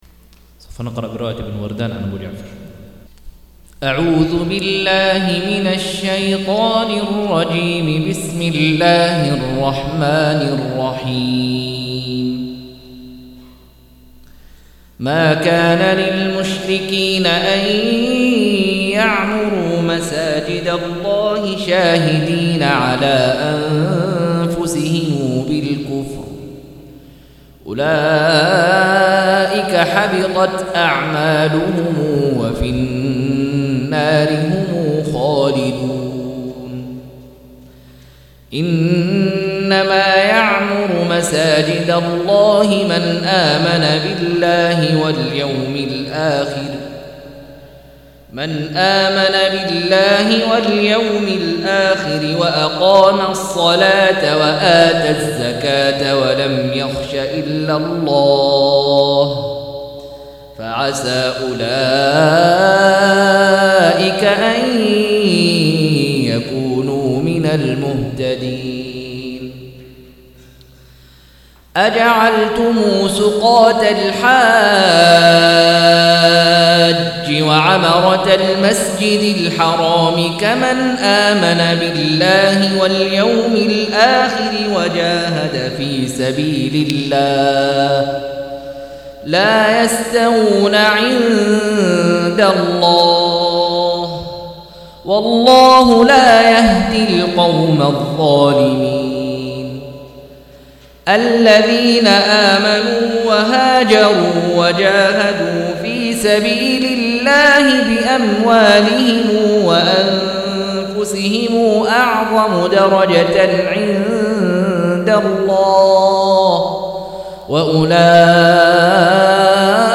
180- عمدة التفسير عن الحافظ ابن كثير رحمه الله للعلامة أحمد شاكر رحمه الله – قراءة وتعليق –